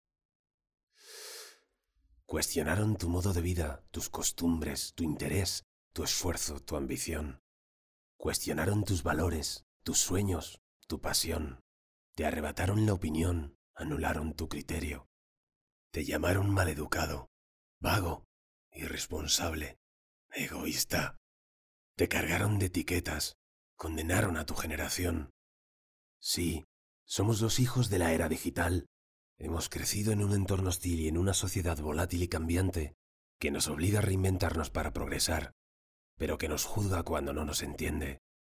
Spanish voice over artist. Will do any voice over in neutral Spanish
kastilisch
Sprechprobe: Sonstiges (Muttersprache):
Clear middle aged voice for any audiovisual project.